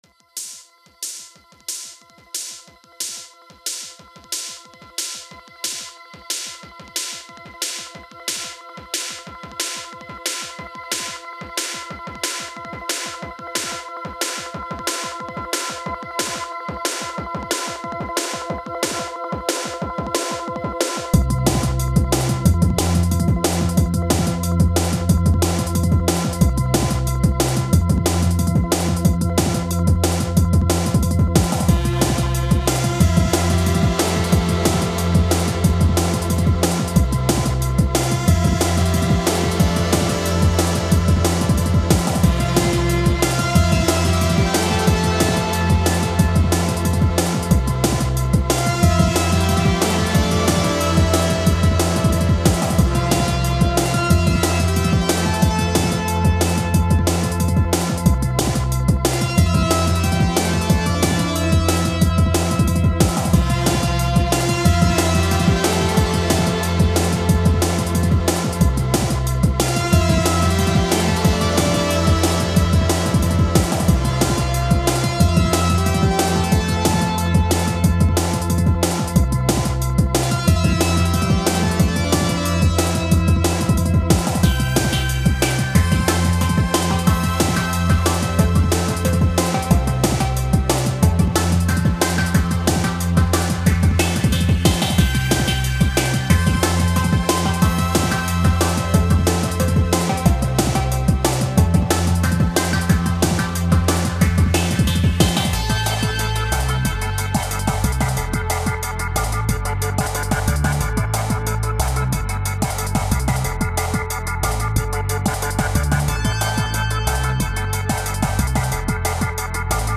It's way too upbeat.
It doesn't sound particularly upbeat - it's got a sort of semi-dystopian sound to it, imo.
dnb2_166.mp3